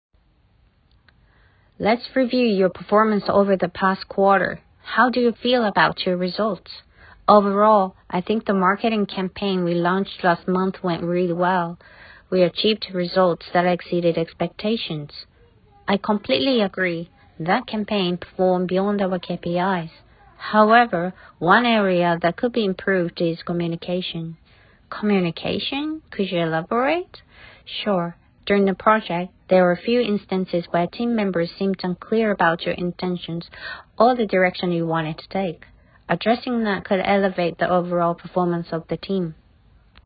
各レベルのモデル音声を掲載しますので、レベル選びや提出時の参考になさってください。
どれも音声変化や発音、リズムを意識した音声となっております。
A, Bと人によって声色を変えてくださる方もいらっしゃいます。